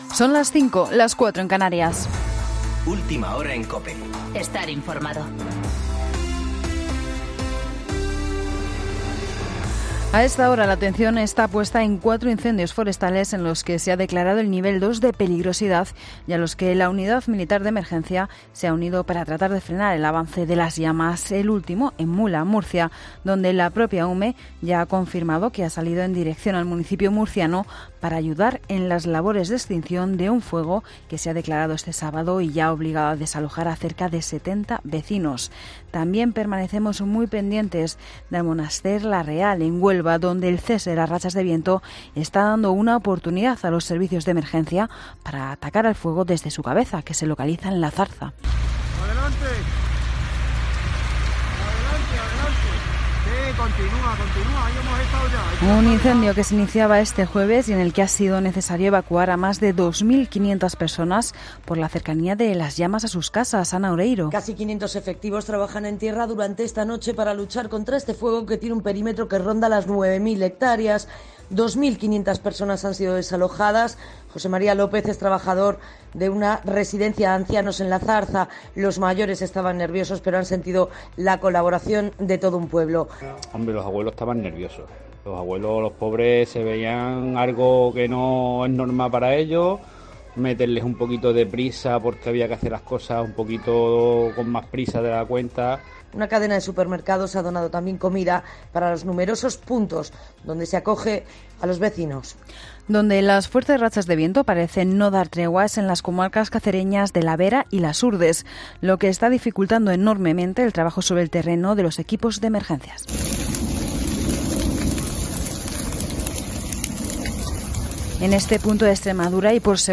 Boletín de noticias COPE del 30 de agosto de 2020 a las 05.00 horas